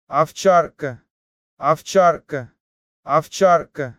SHEPHERD - OVCHARKA (ov-TCHAR-ka), ОВЧАРКА